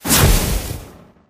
攻击音效
CR_firecracker_atk_hit_01.mp3